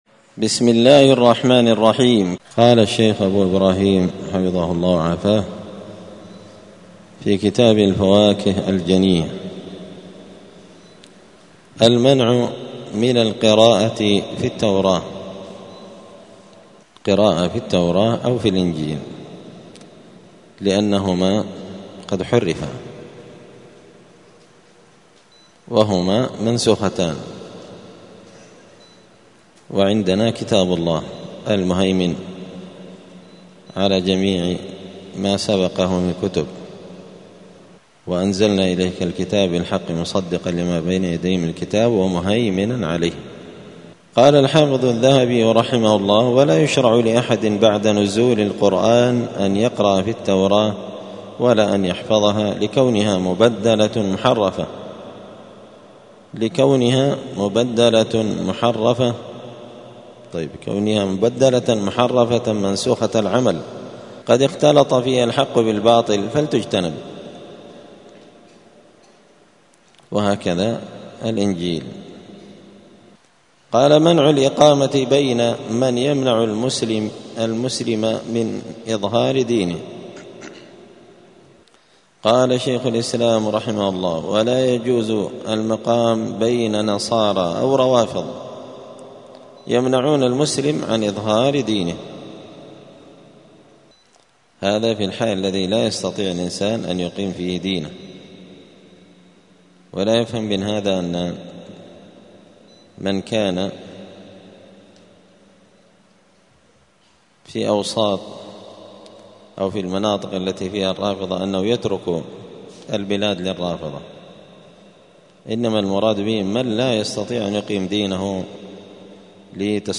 دار الحديث السلفية بمسجد الفرقان بقشن المهرة اليمن
43الدرس-الثالث-والأربعون-من-كتاب-الفواكه-الجنية.mp3